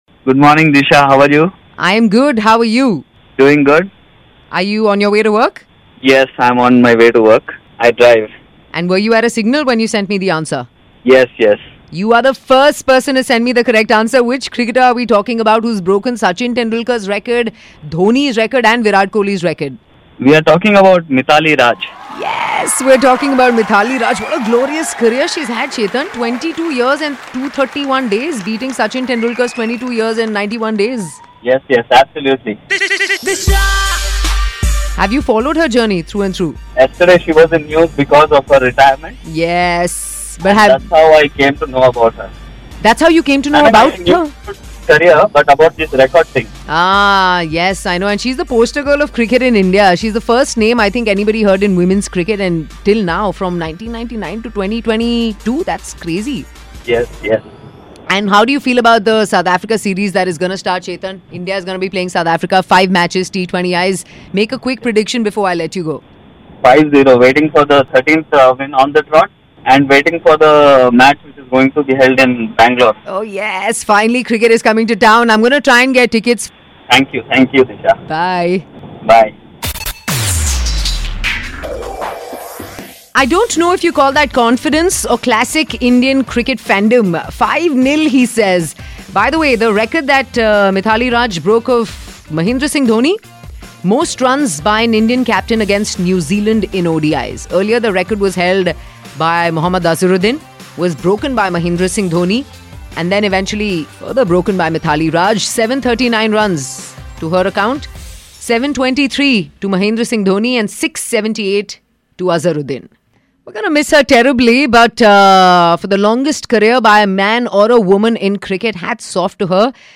talks to a listener about Indian Cricket Legend Mithali Raj!